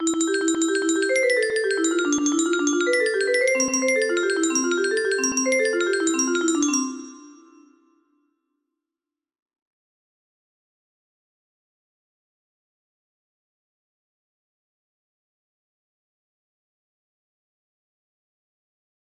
Lullaby - brahms music box melody